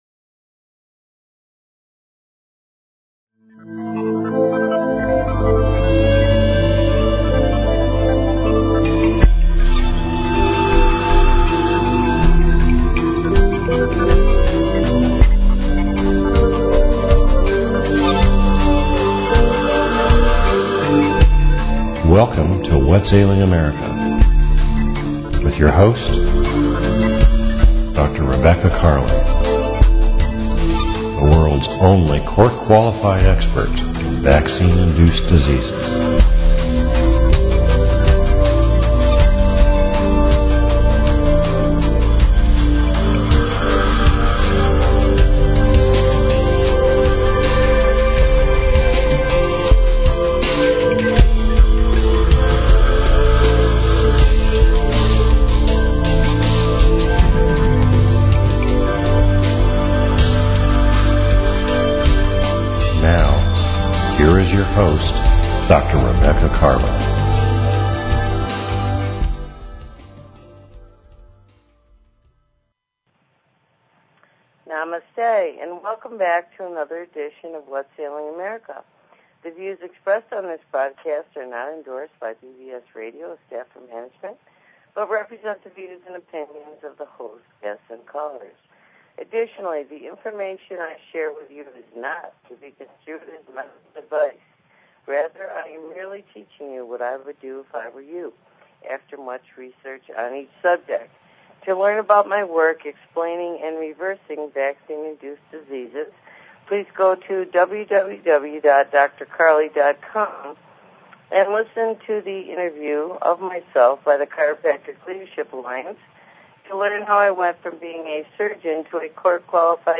Talk Show Episode, Audio Podcast, Whats_Ailing_America and Courtesy of BBS Radio on , show guests , about , categorized as